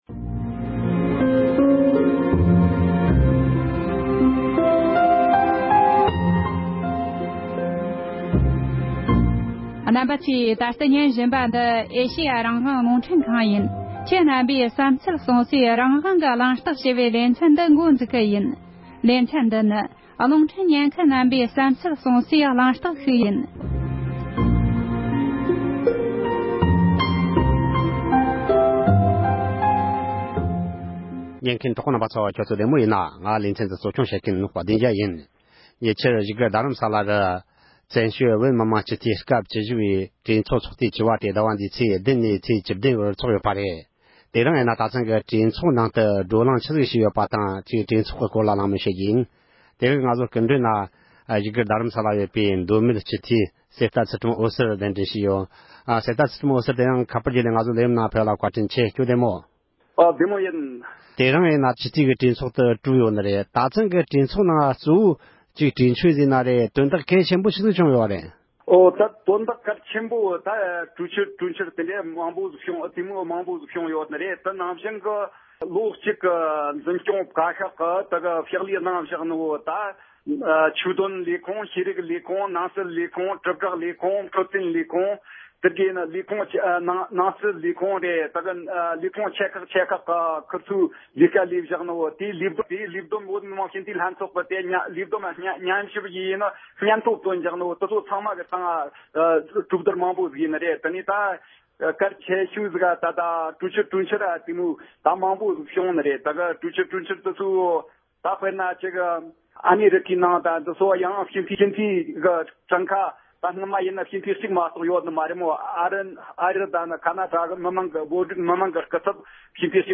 བཙན་བྱོལ་བོད་མི་མང་སྤྱི་འཐུས་སྐབས་བཅུ་བཞི་པའི་གྲོས་ཚོགས་ཚོགས་དུས་བཅུ་པའི་སྐོར་གླེང་མོལ་ཞུས་པ།